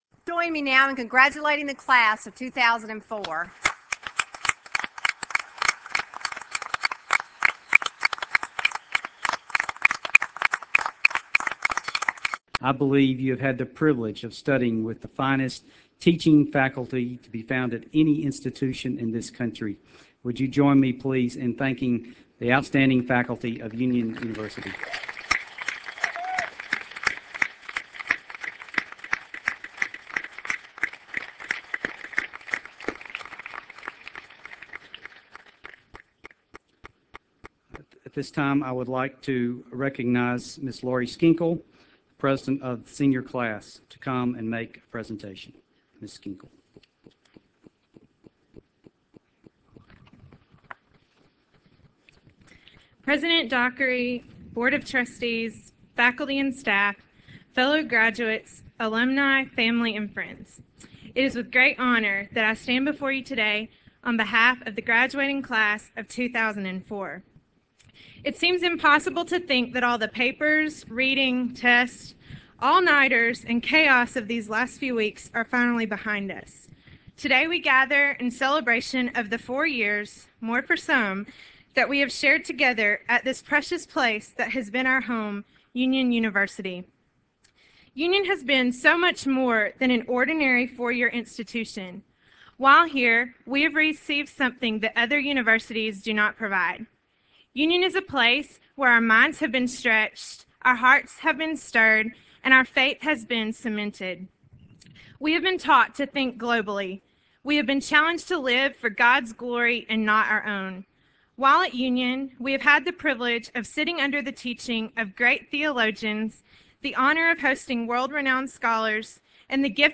Spring 2004 Commencement: Closing
Presentation of Awards Charge to the Graduates and Pledge